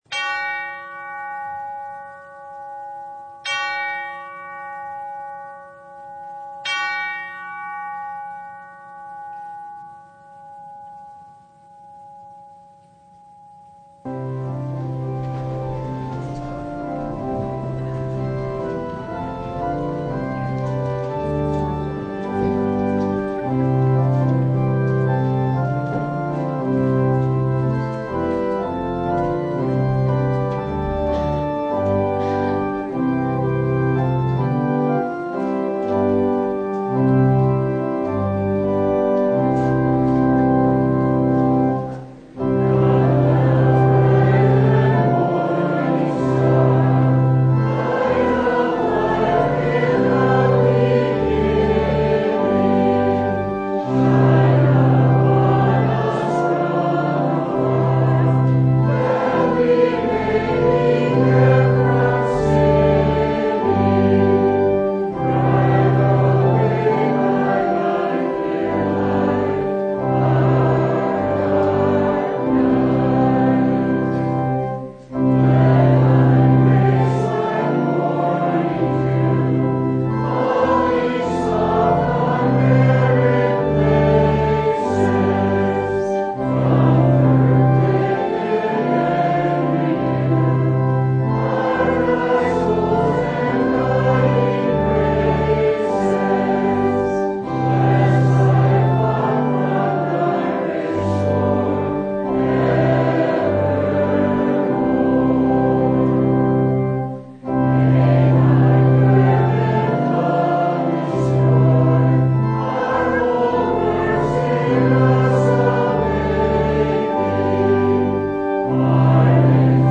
Download Files Bulletin Topics: Full Service « The Star Maker What an Epiphany!